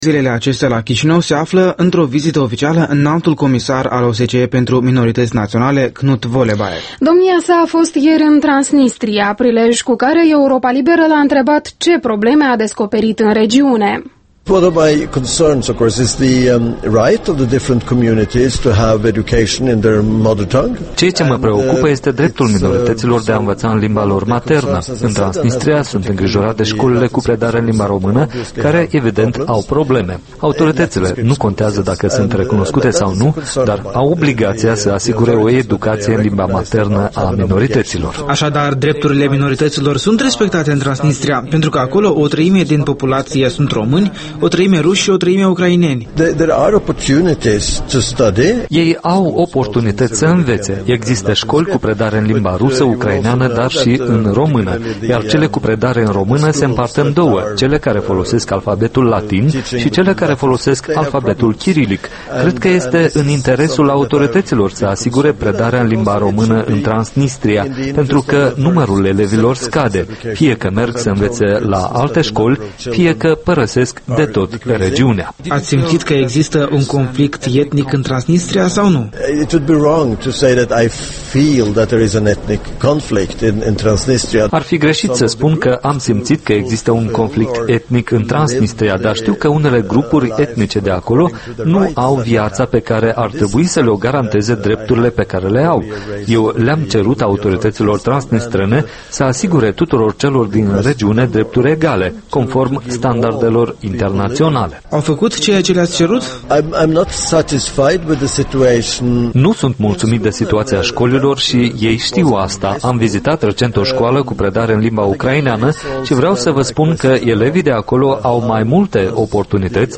Interviul matinal EL: Kurt Vollebaek despre drepturile minorităților și societățile multiculturale